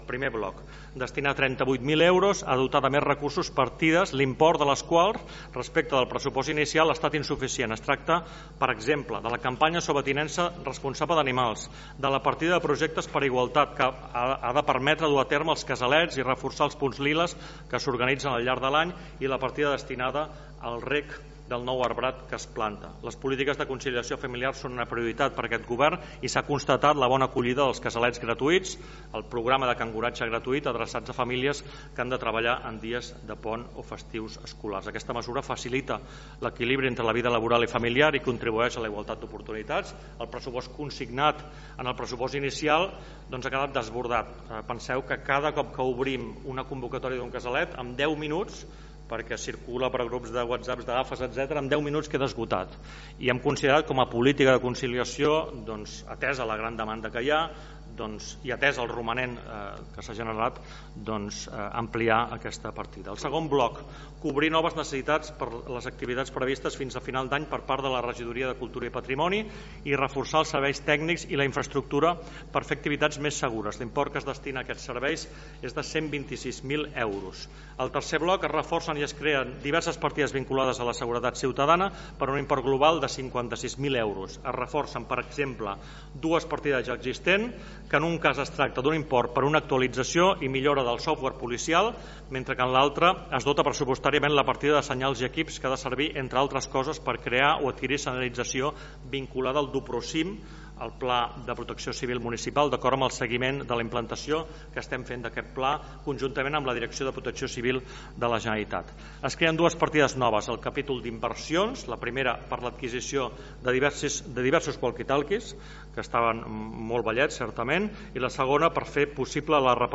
El ple d’aquest dimarts 1 de juliol ha aprovat el destí del superàvit del 2024.
La segona modificació aplica part del romanent -657.000 euros- per finançar despeses que no poden demorar-se fins a l’exercici següent, per les quals no hi ha crèdit o el consignat al pressupost vigent és insuficient. L’alcalde de Tiana, Isaac Salvatierra, va detallar-les: